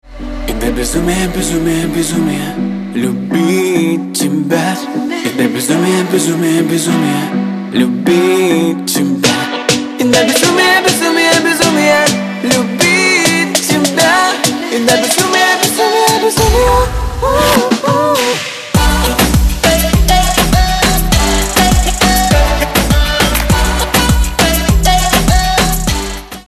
• Качество: 128, Stereo
поп
мужской вокал
dance